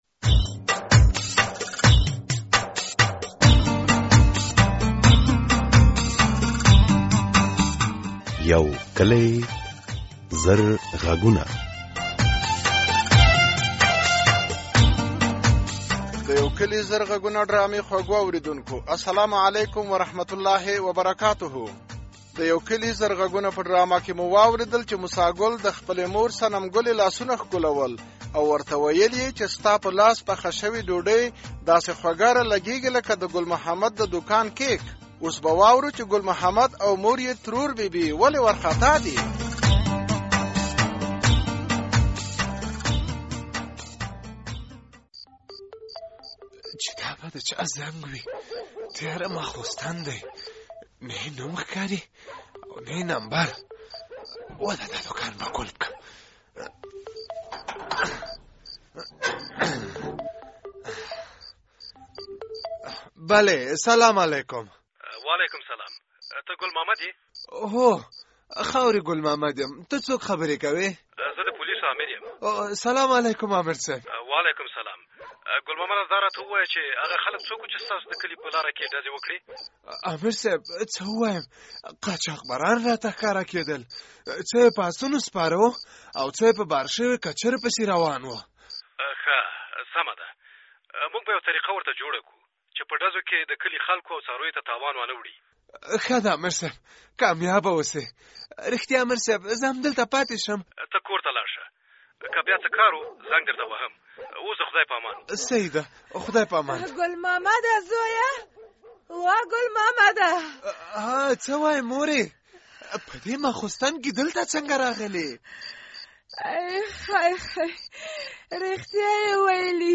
د یو کلي زرغږونو د ډرامې په دې برخه کې اوری چې ناصر ته یې پلار نصیحت کوي تر څو نجونې و نه زوروي....